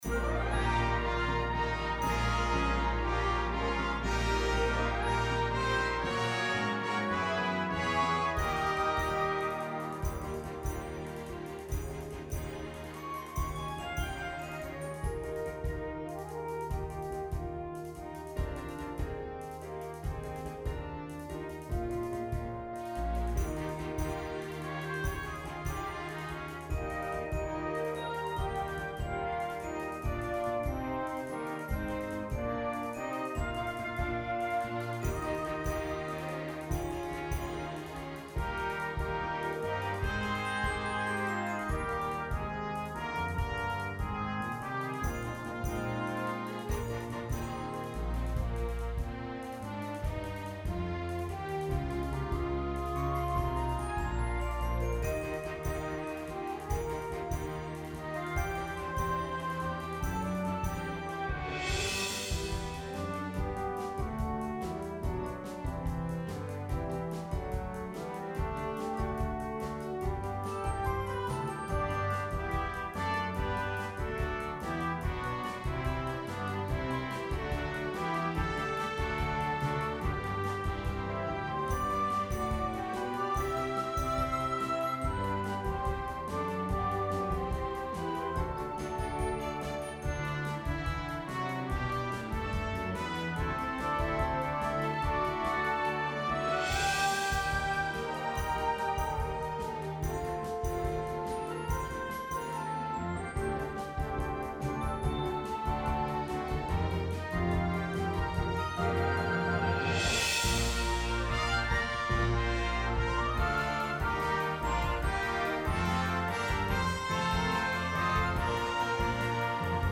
A modern and energetic setting
Arranged for orchestra and rhythm section.